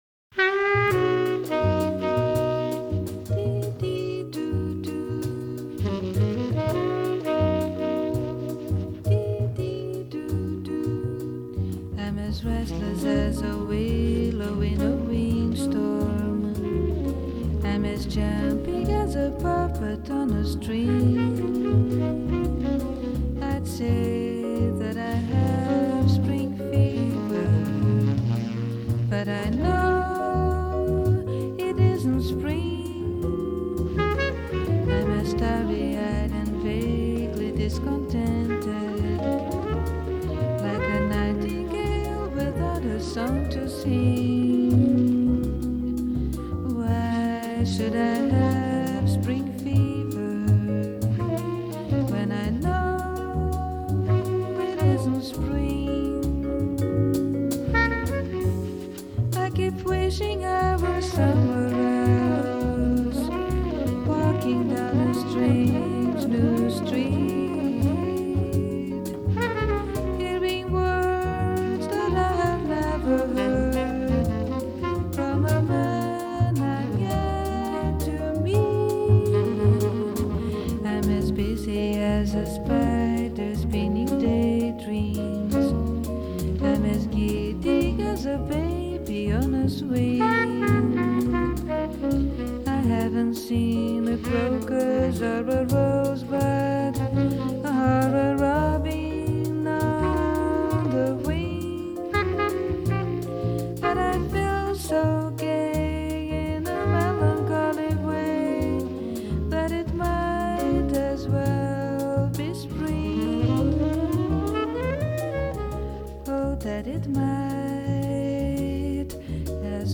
bossa nova and jazz standards